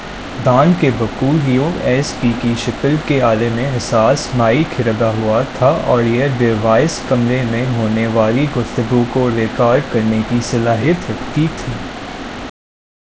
Spoofed_TTS/Speaker_07/114.wav · CSALT/deepfake_detection_dataset_urdu at main